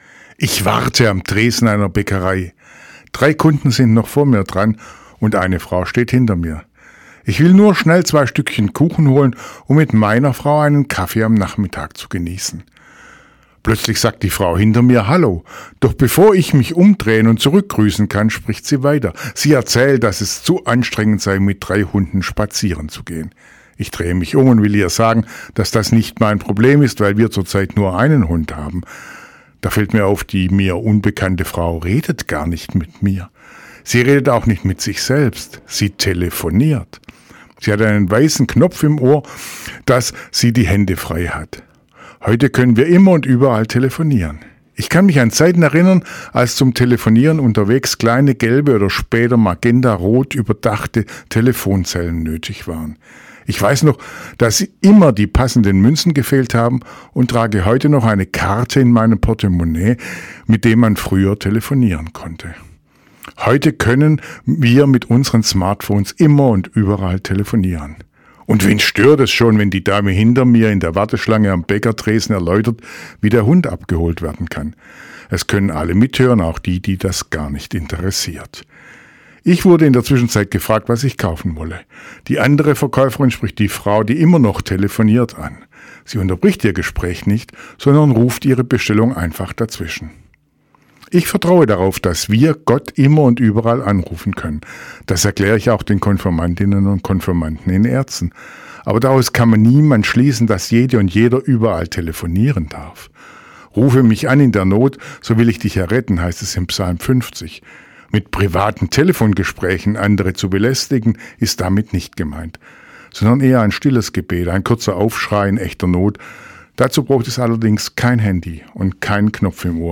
Radioandacht vom 3. Februar